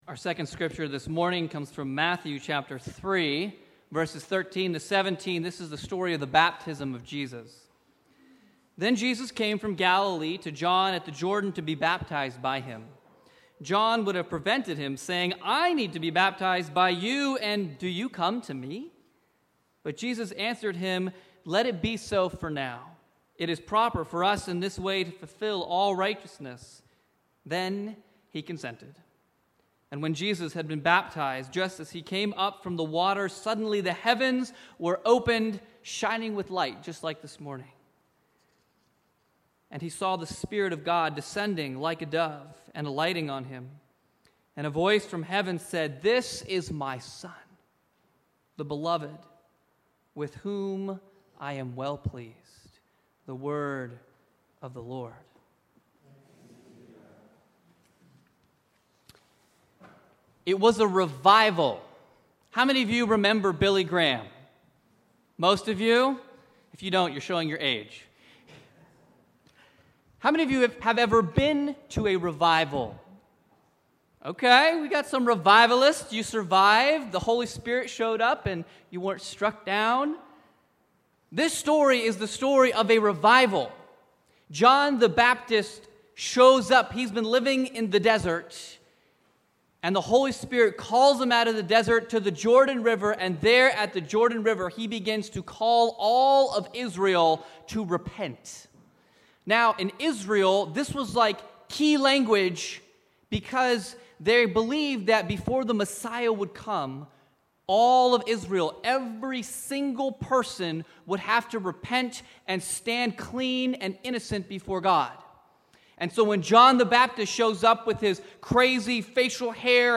Sermon 1/12/2020 Down by the Riverside - Wrightsville Presbyterian Church